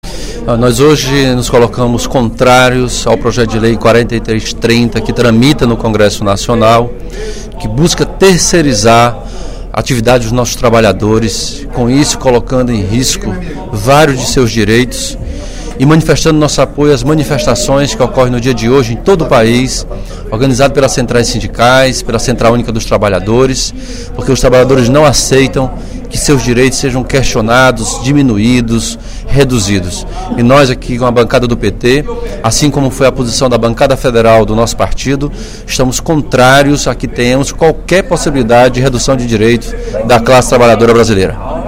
O deputado Capitão Wagner (PR) criticou, no primeiro expediente da sessão plenária da Assembleia Legislativa desta quarta-feira (15/04), parceria firmada entre a Prefeitura de Fortaleza e Bloomberg Philanthropies, entidade norte-americana.